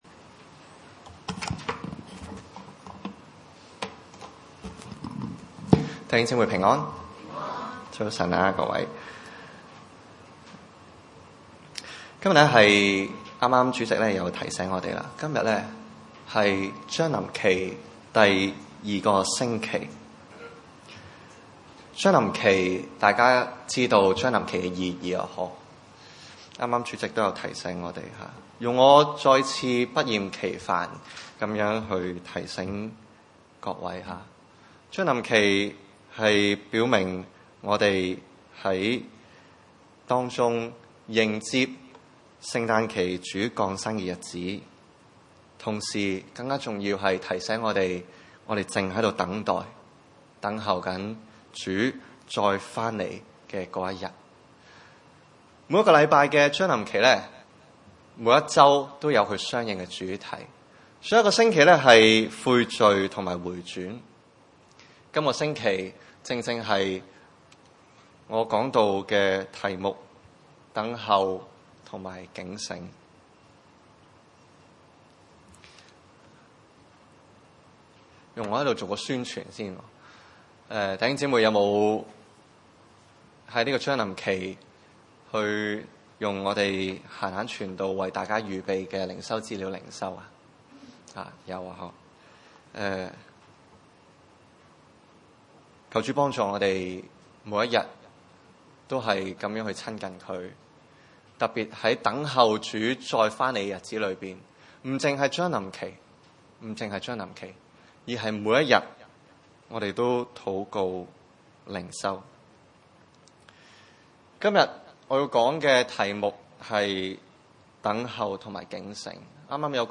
路加福音 18:1-14 崇拜類別: 主日午堂崇拜 1 耶 穌 設 一 個 比 喻 ， 是 要 人 常 常 禱 告 ， 不 可 灰 心 。